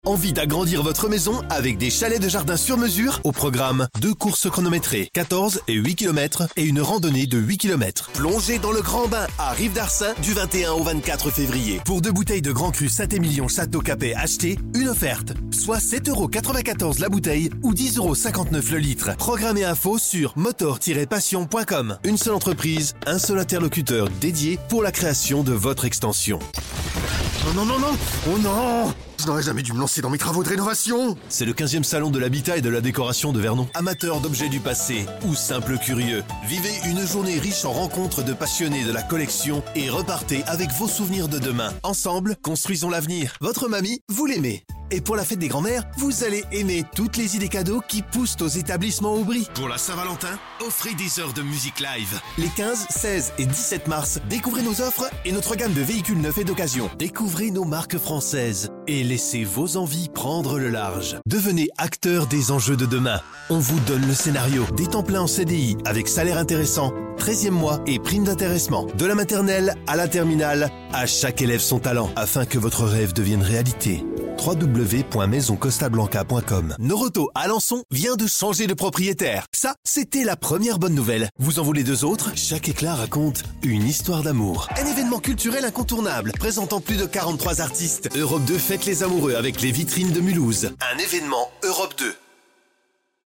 Announcements
I am a professional french voicer over from 5 years with a smooth young voice, with some pretty bass; i can easily add modulations on my voice.
Isolated Cabin